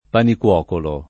panicuocolo [ panik U0 kolo ]